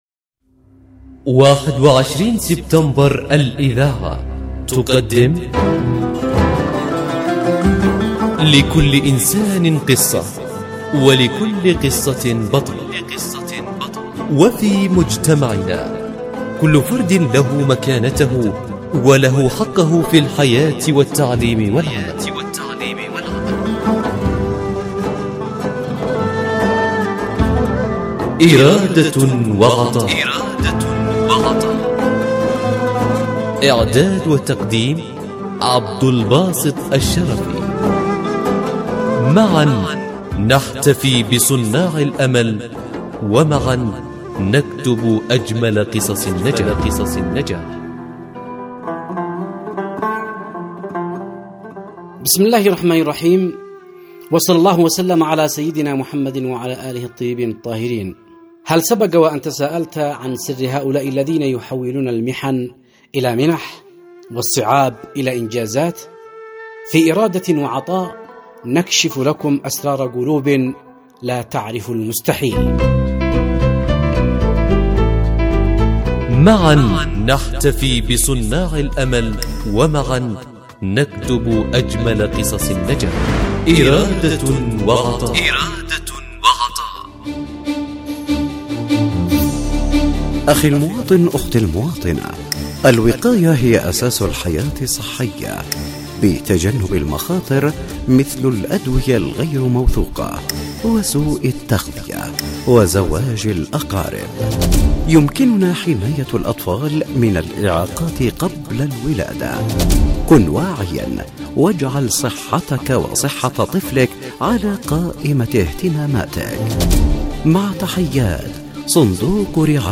برنامج “إرادة وعطاء” يأخذكم في رحلة إذاعية قصيرة ، نستكشف خلالها عالماً مليئاً بالتحدي والإصرار. نسلط الضوء على قصص ملهمة لأشخاص من ذوي الهمم، أثبتوا أن الإعاقة لا تحد من العطاء بل تزيده قوة وإبداعاً.